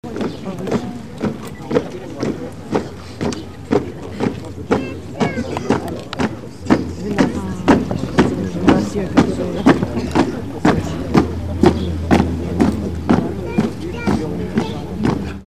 Звуки марша солдат
Солдаты шагают в строю